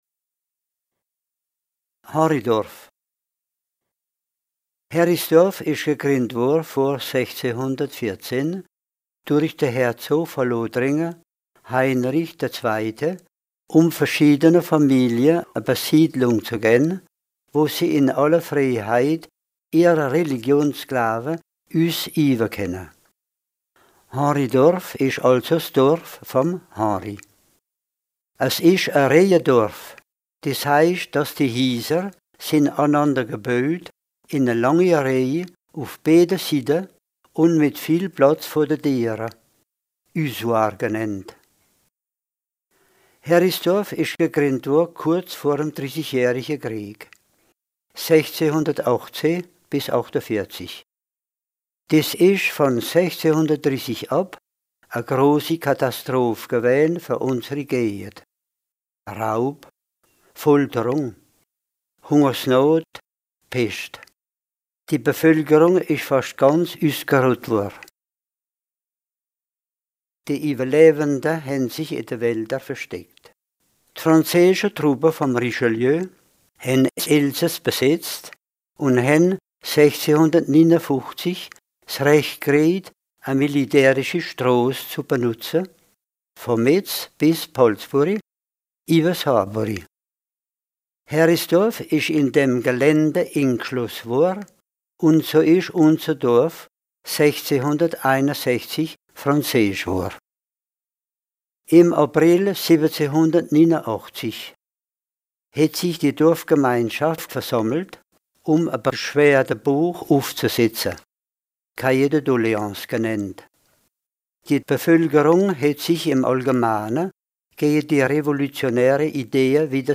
Contes et récits enregistrés dans les communes de Dabo, Hultehouse, Phalsbourg, Henridorff et de Berling.
21 Henridorff - Autor und Leser